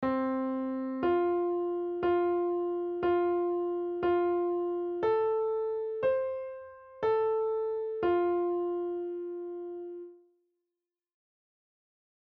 On the piano, play The Wheels On The Bus
C F F F F
A >C A F